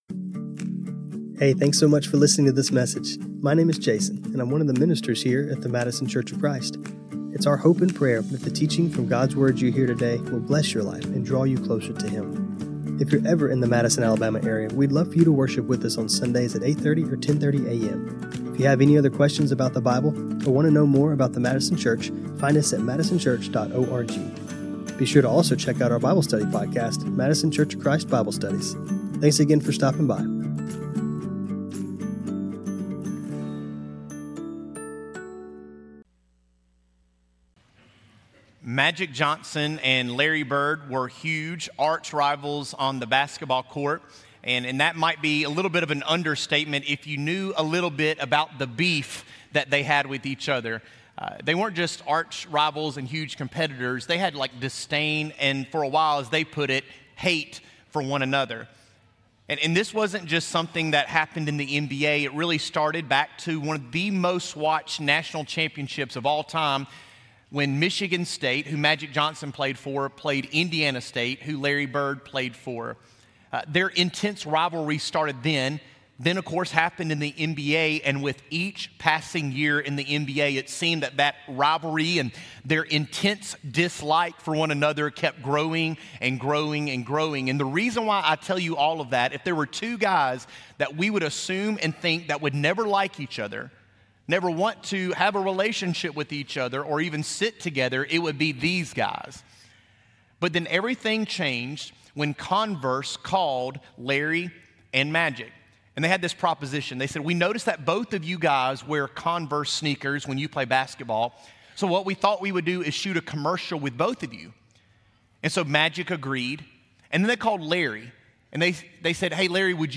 This sermon was recorded on Feb 16, 2025.